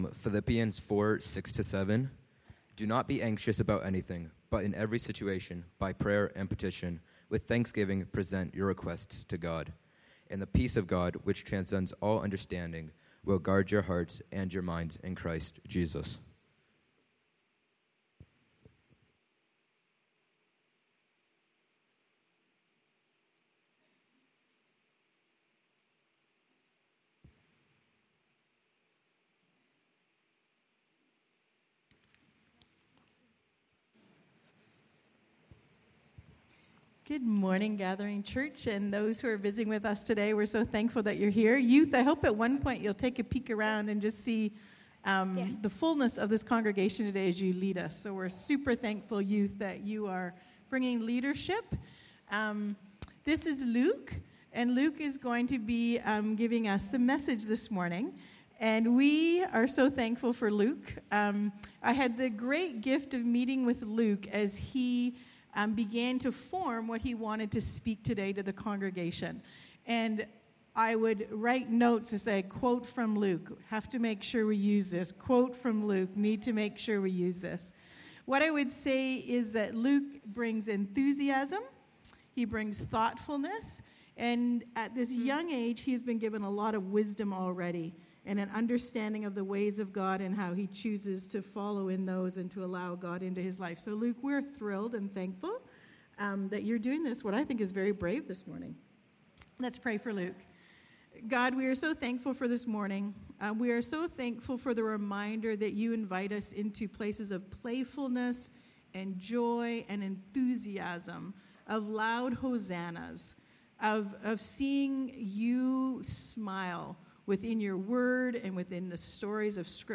Youth Service - Like a Tree Planted